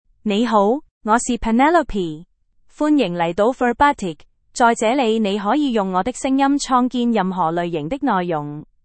PenelopeFemale Chinese AI voice
Penelope is a female AI voice for Chinese (Cantonese, Hong Kong).
Voice sample
Penelope delivers clear pronunciation with authentic Cantonese, Hong Kong Chinese intonation, making your content sound professionally produced.